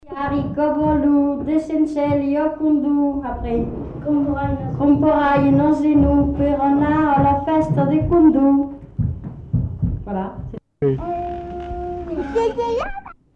enquêtes sonores
Formulette d'élimination